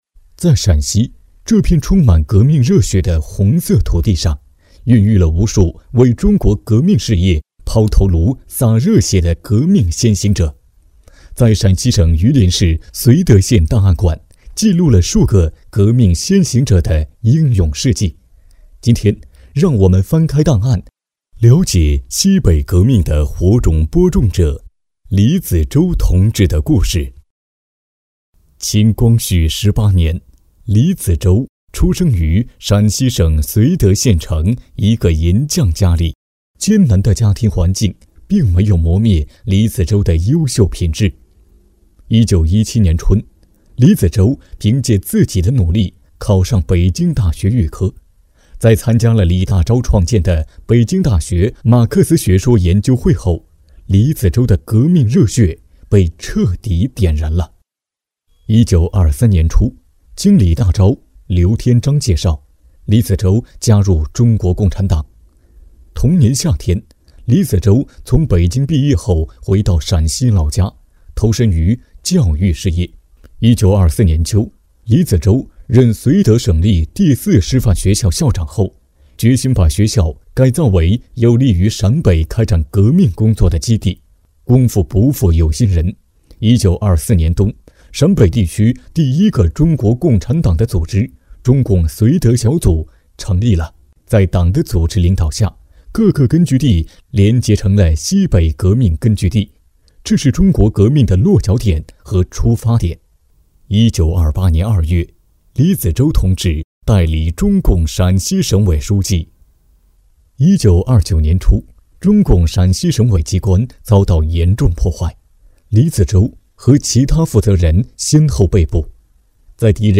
【红色档案诵读展播】西北革命火种的播种者——李子洲同志的故事